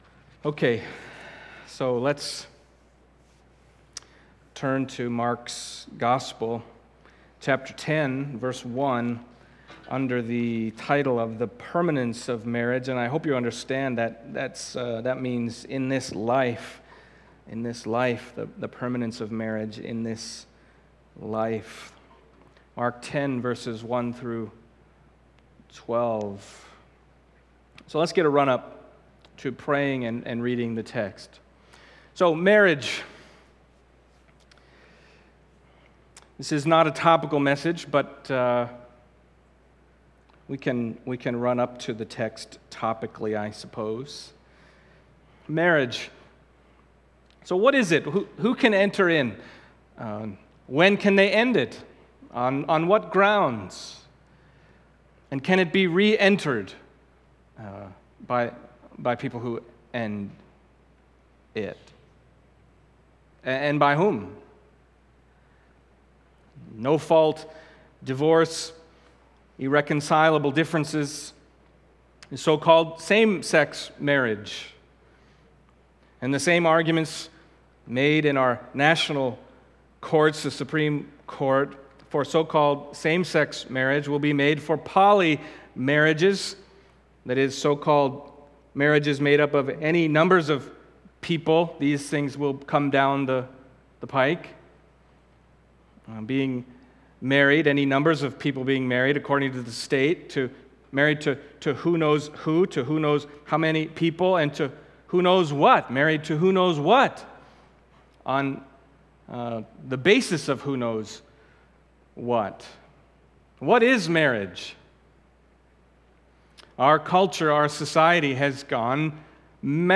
Mark Passage: Mark 10:1-12 Service Type: Sunday Morning Mark 10:1-12 « Discipleship Explored